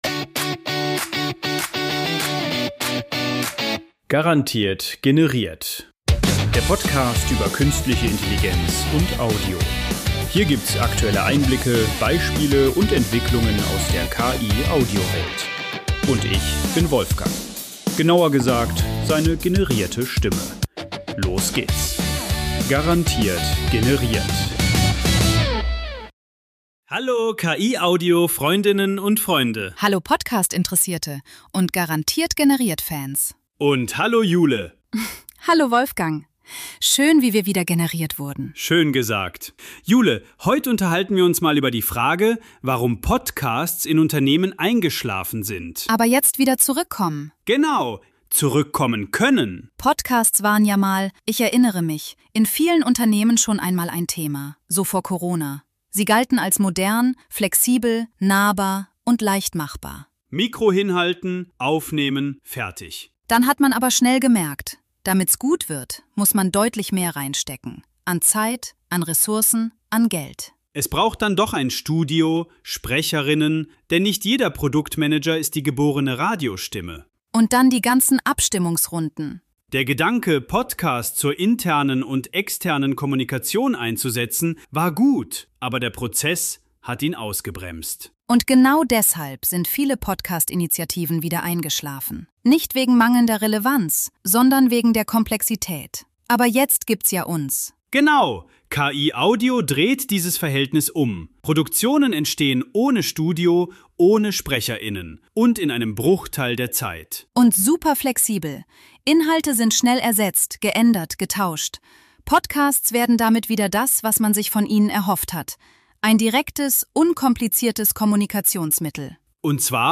Bei "Garantiert generiert" sind alle Inhalte, Töne, Dialoge, Sounds
künstlich generiert.